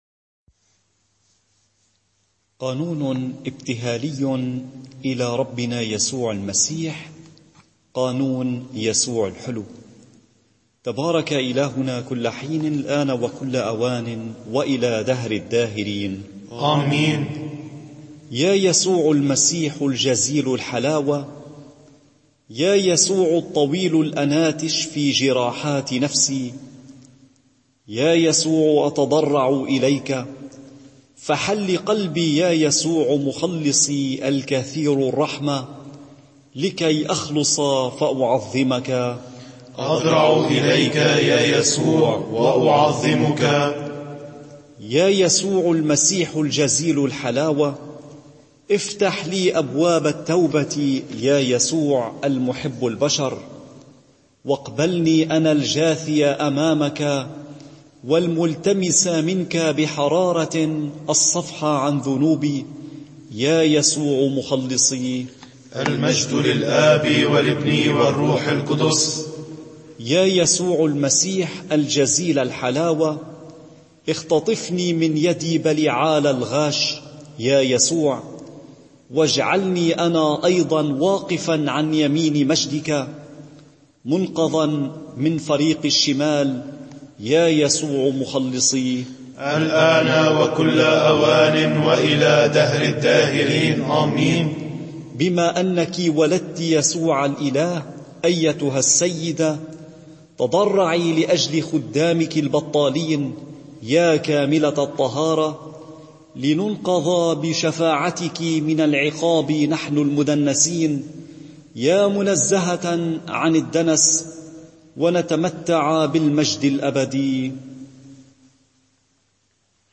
قانون يسوع الحلو رهبان